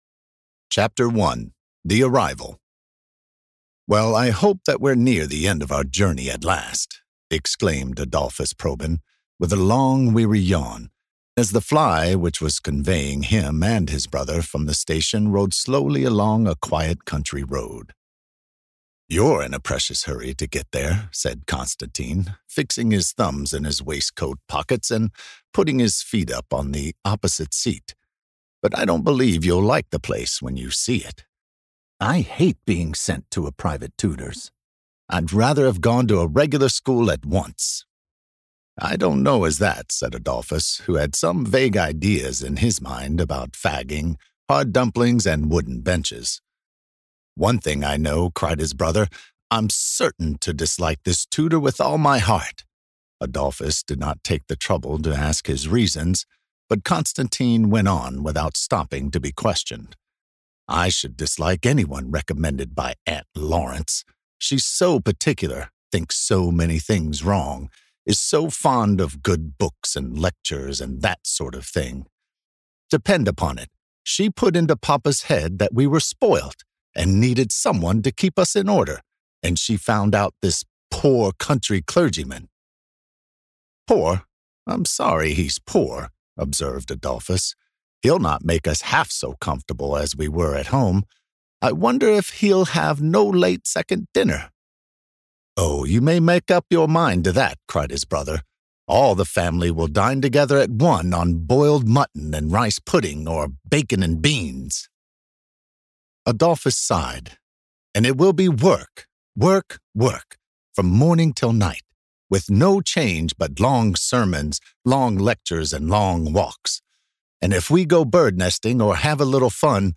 The Giant Killer - Audiobook